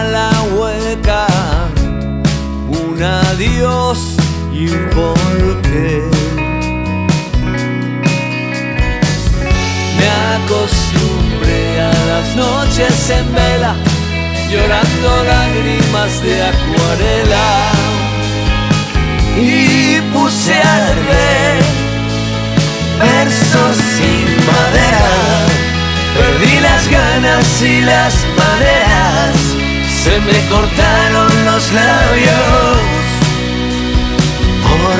Жанр: Поп музыка / Рок
Pop, Rock